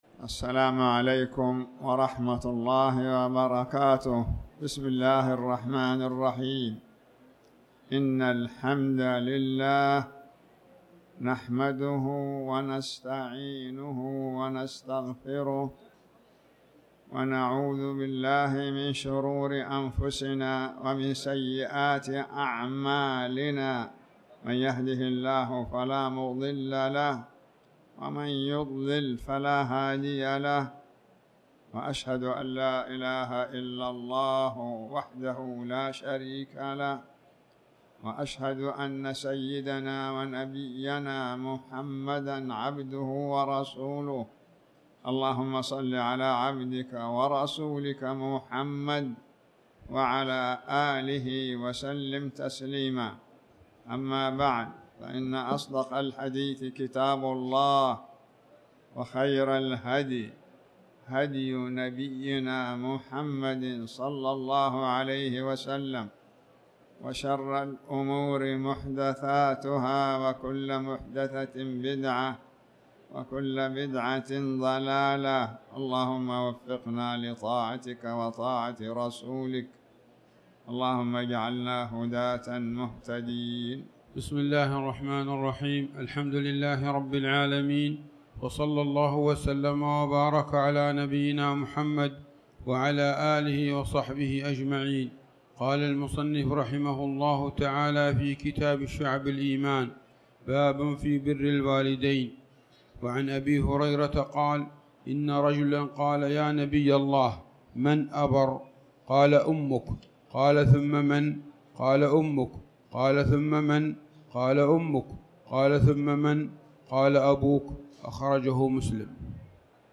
تاريخ النشر ٢٢ شعبان ١٤٣٩ هـ المكان: المسجد الحرام الشيخ